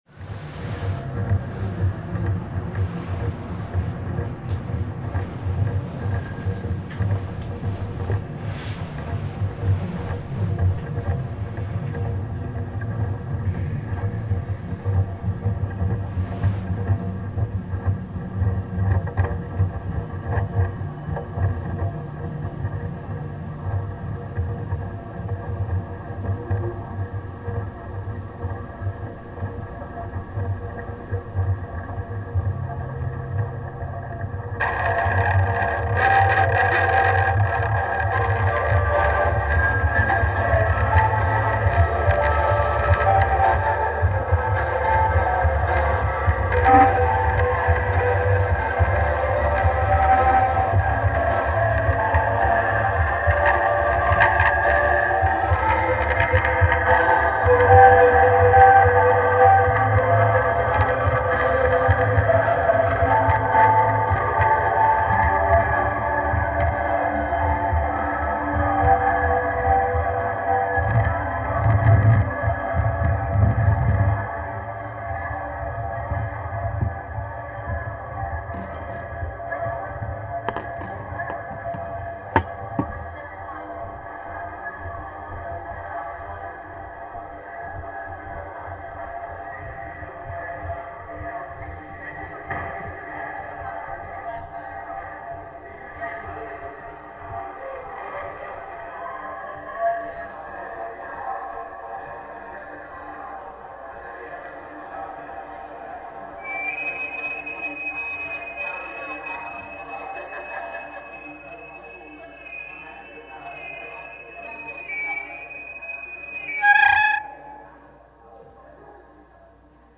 french horn + ny recordings
alto saxophone + london recordings